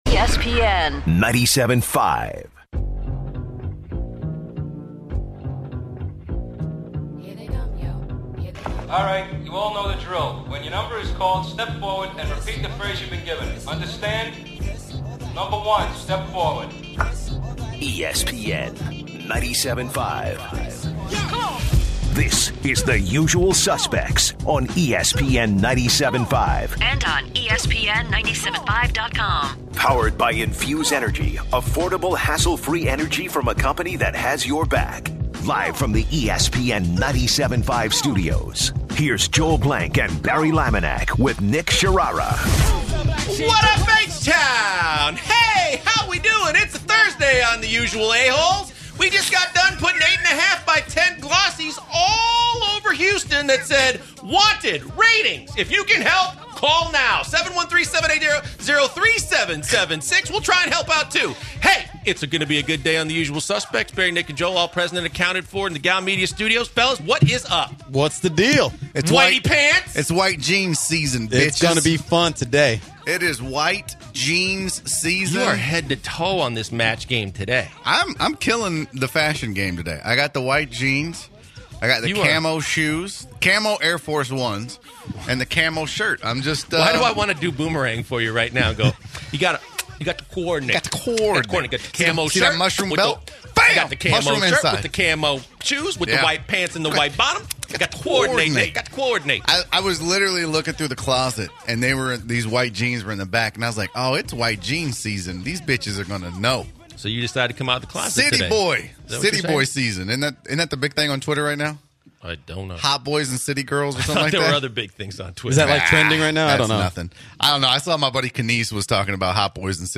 They give Astros and Ranger's statistics. They discuss how the Rockets are working on three-team trade In order to acquire Westbrook. The guys take calls from listeners and talk about Astros.